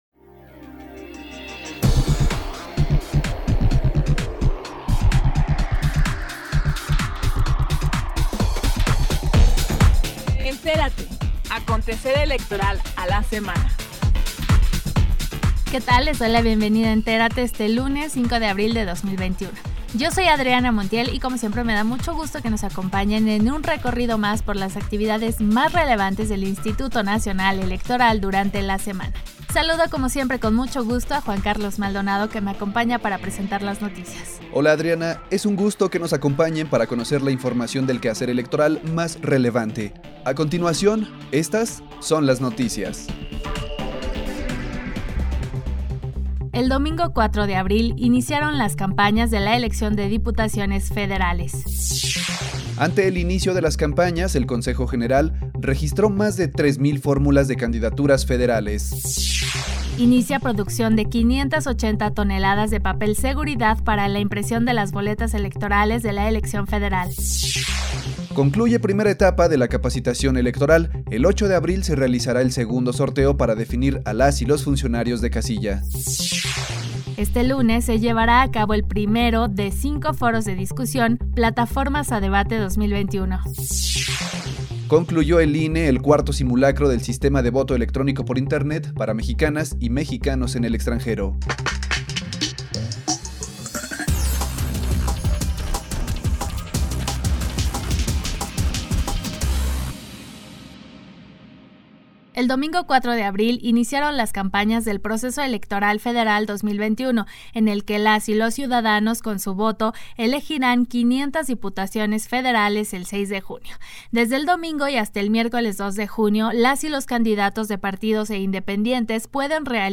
NOTICIARIO 05 DE ABRIL 2021